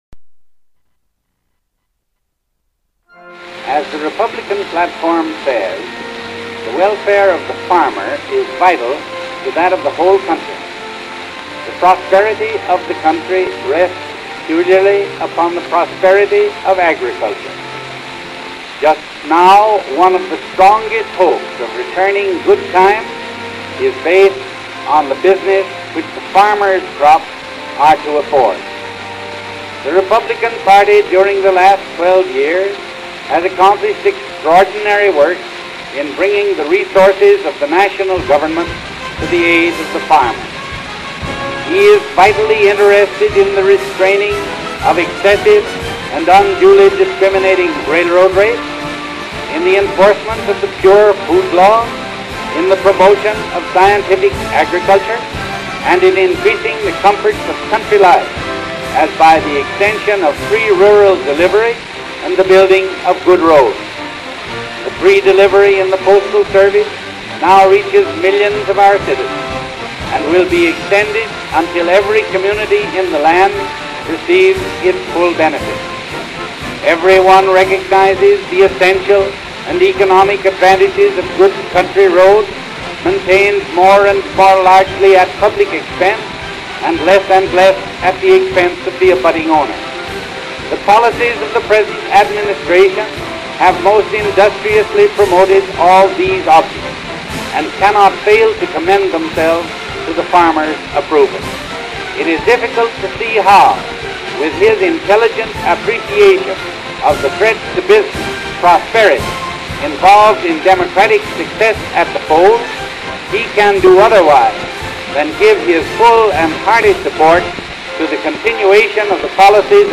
Held during our recent Christmas Week festivities, this exciting event offered students, faculty, staff, administrators, and even visiting accreditation team members the chance to present their favorite rhetorical milestones from American history set to the musical accompaniment of diverse contemporary songs and dances.
Project Title: William H. Taft's "The Farmer and the Republican Party" (1908) to the accompaniment of The West Point Band's Graduation March.